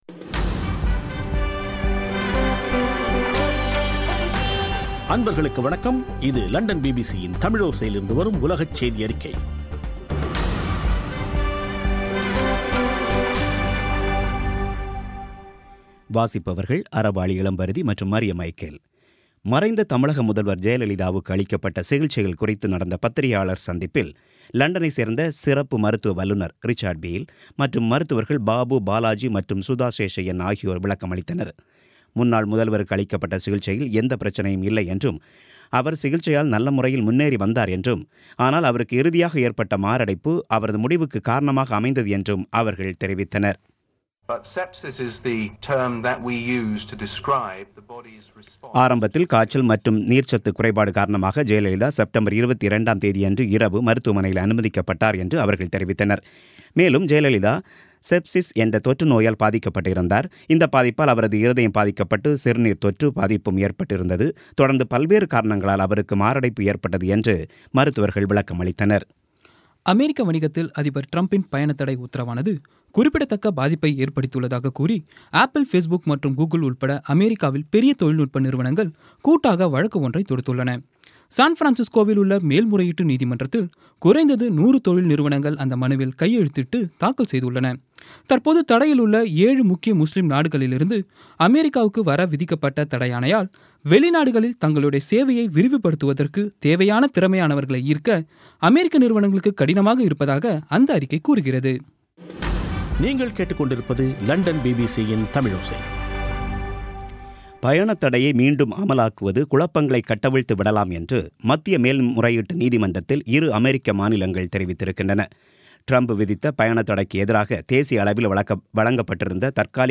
பிபிசி தமிழோசை செய்தியறிக்கை (06/02/17)